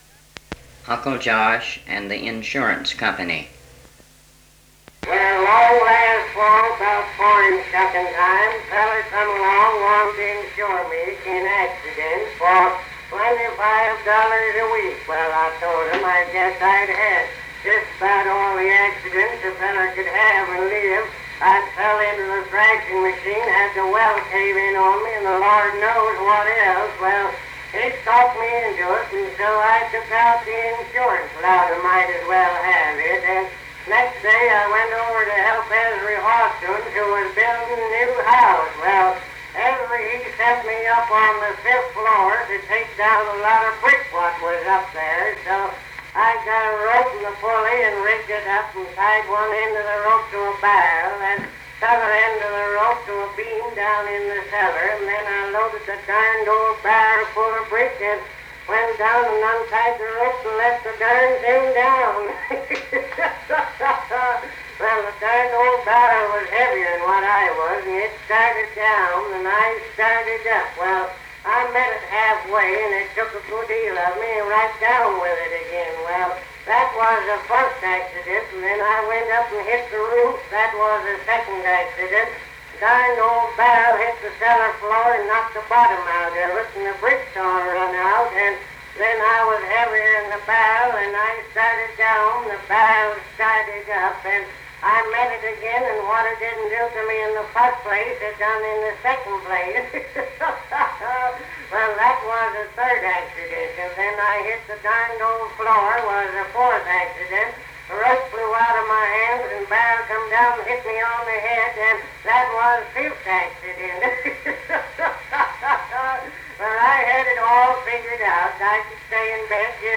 Cal Stewart's comedic routine, Uncle Josh and the insurance company..